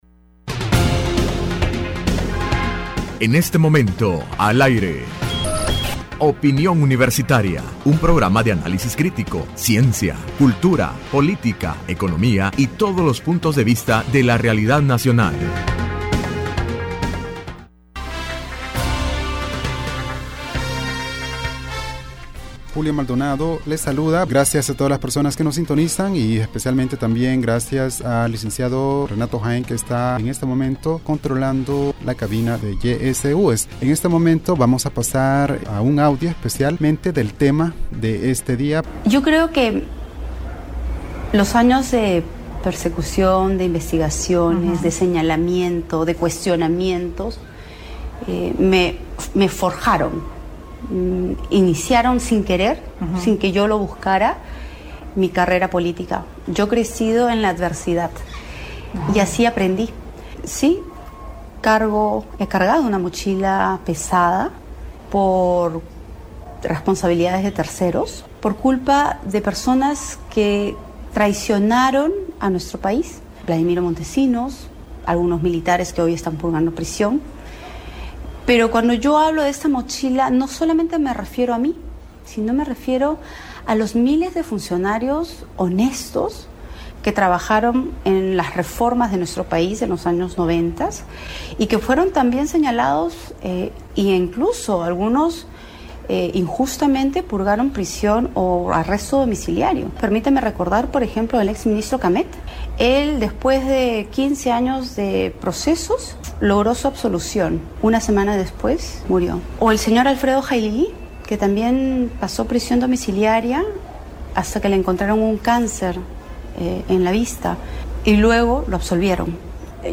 Entrevista Opinión Universitaria (20 Abril 2016) : Proceso de elecciones en el Perú ante la candidatura de Keiko Fujimori hija del Ex-presidente Alberto Fujimori.